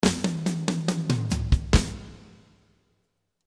this slow rock style is in 70 bpm, the 10 free drum loops are with tom fills and Cymbals, the 10 drum loops are available for download.
Classic Rock style, this product contains 39 loops with Cymbals and tom fills.
Because it is in 70 bpm the snare have long reverb.